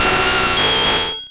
Cri de Nidoking dans Pokémon Rouge et Bleu.